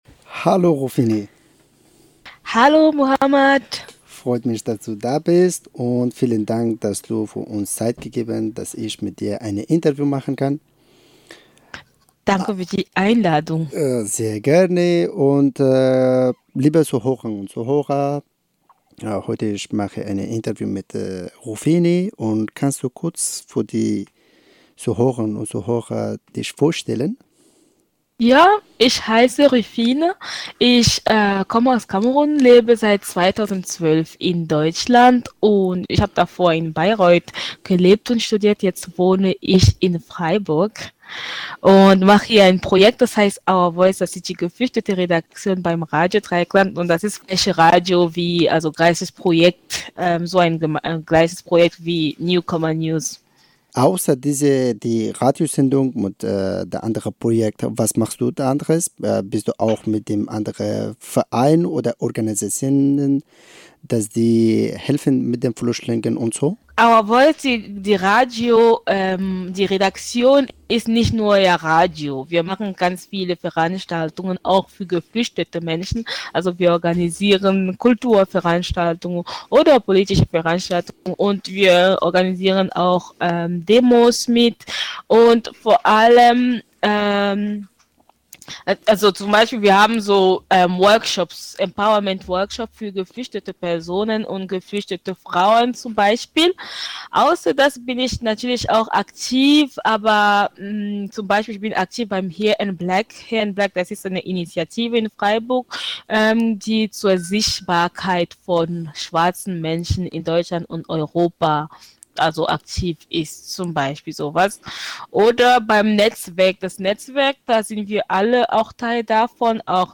BePart :: Porträt